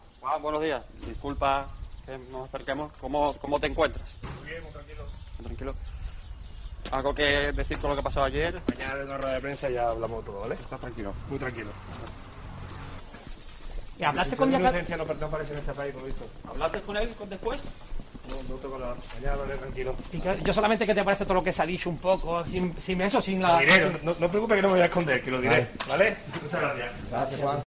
Las cámaras de El Golazo han hablado brevemente con Juan Cala: "Estoy muy tranquilo, no me voy a esconder. Mañana hablaré"